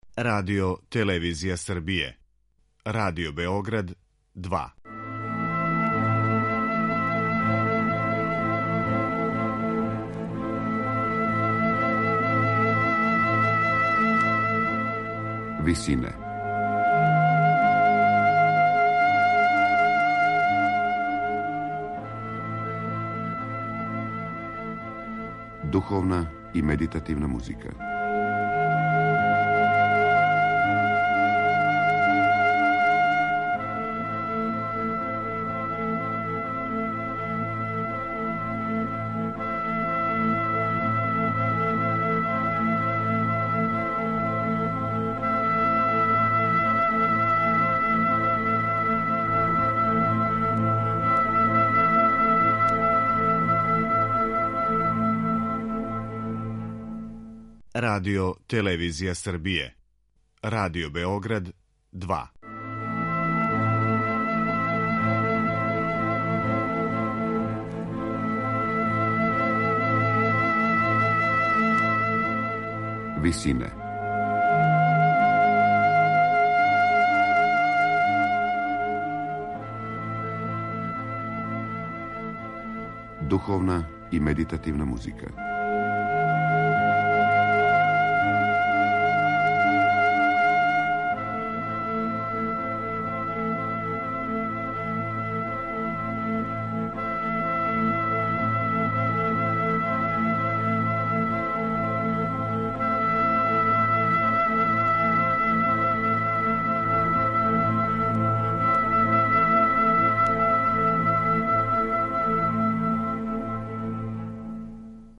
Музика за лауту
На крају програма, у ВИСИНАМА представљамо  медитативне  и духовне композиције аутора свих конфесија и епоха.
Капсбергеров стил, посебно у токатама, одликују изненадне промене, оштри контрасти, необични ритмички обрасци и врло често одступања од уобичајених контрапунктских правила.